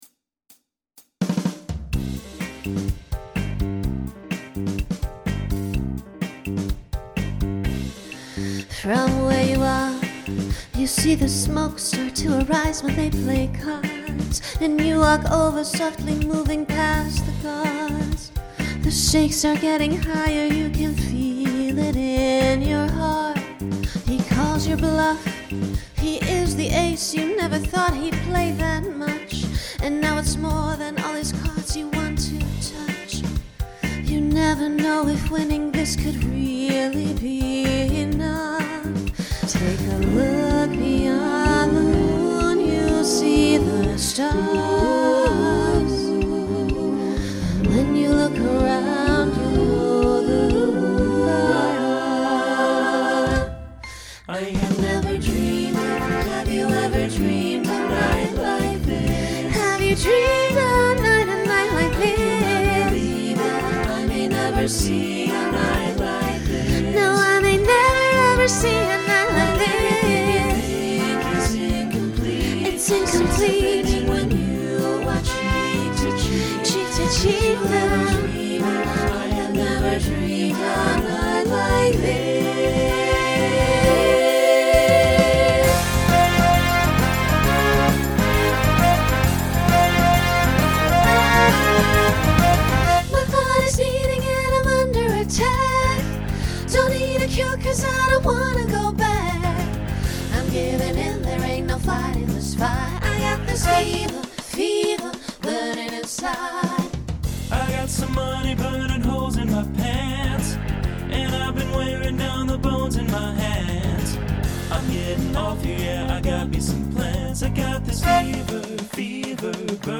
Instrumental combo
Story/Theme Voicing SATB